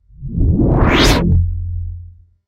A building electrical power surge with rising hum, peak buzz, and sudden cutoff
power-surge.mp3